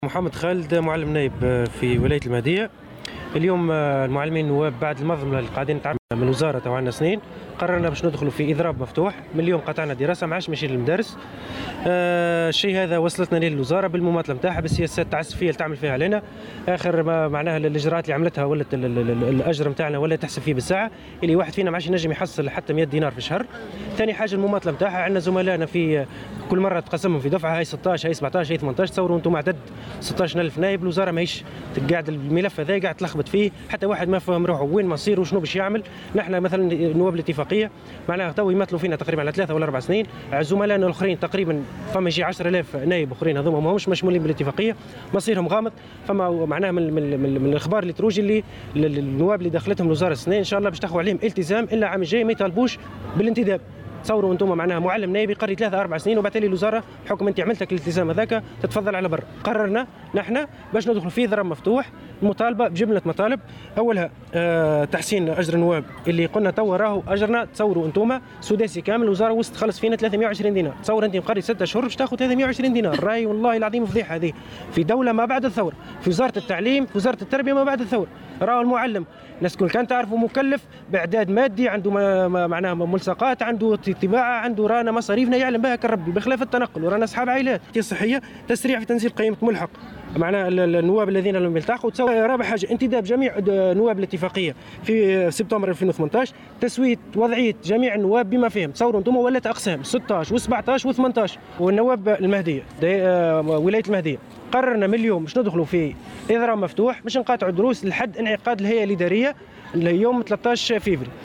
Un des protestataires a affirmé au micro du correspondant de Jawhara FM dans la région que les principales revendications des enseignants suppléants consistent en la régularisation de la situation de tous les suppléants et l'augmentation des salaires.